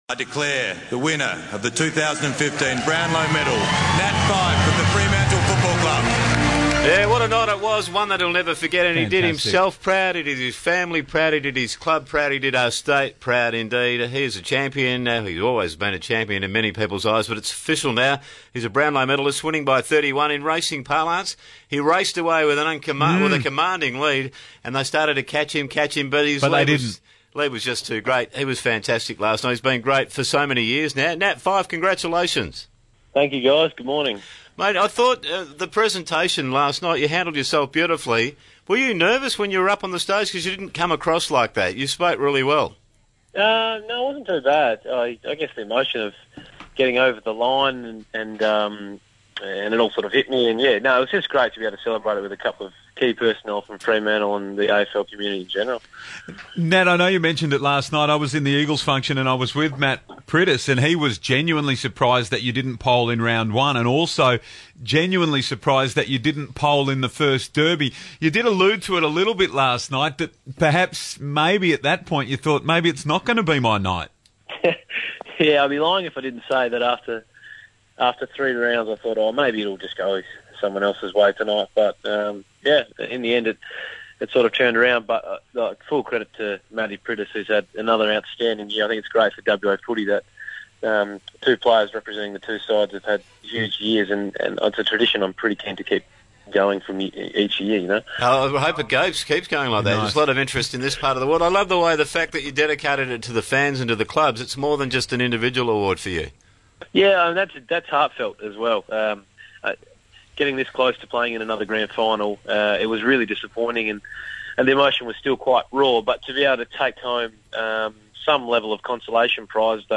Nat Fyfe spoke to 6PR Breakfast following the Brownlow Medal night